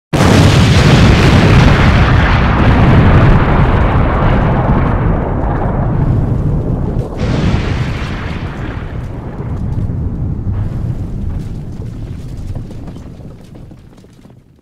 PLAY ExplosionSFX